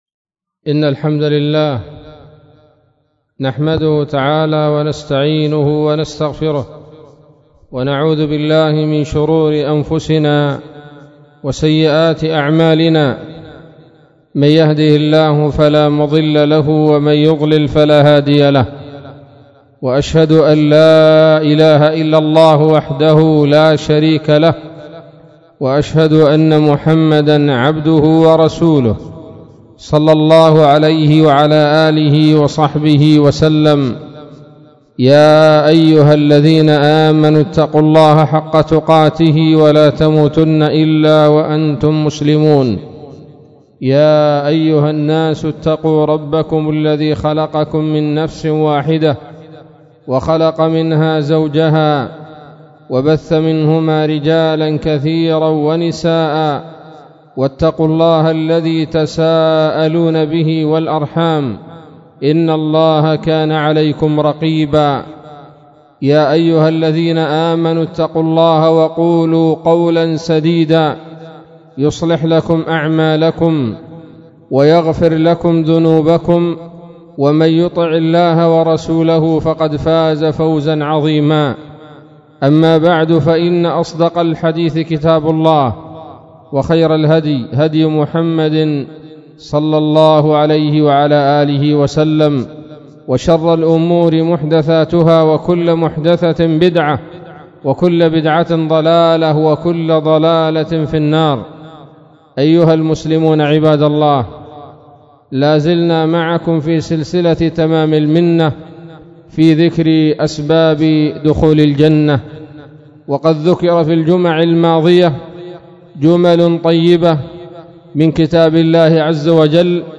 خطبة بعنوان : ((تمام المنة في ذكر بعض أسباب دخول الجنة [5])) 01 ربيع الثاني 1438 هـ